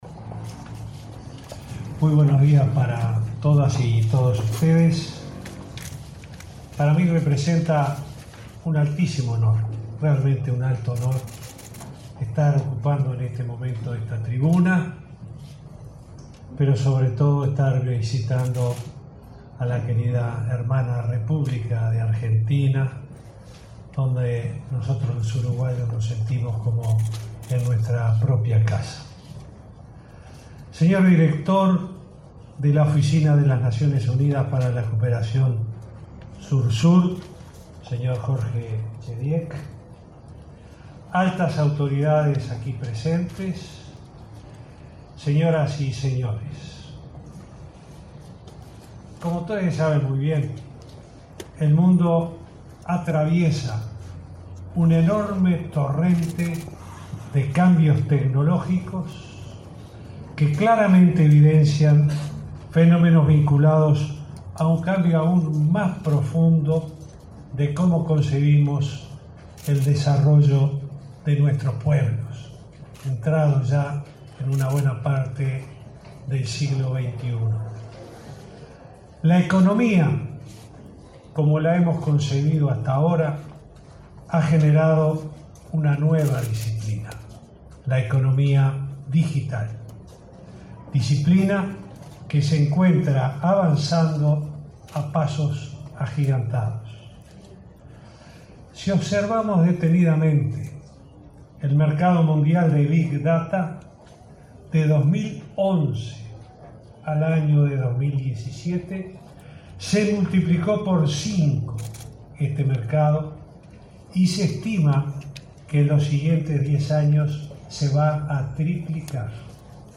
En el marco de una jornada sobre era digital previa a la conferencia de Naciones Unidas sobre cooperación Sur-Sur, el presidente Vázquez destacó en Buenos Aires que el 80 % de los uruguayos está conectado a Internet y que, de ese total, el 75 % accedió a fibra óptica. Dijo que la penetración de celulares es del 154 % y que en los centros educativos hay Internet. Habló del cable submarino y de los planes Ceibal e Ibirapitá.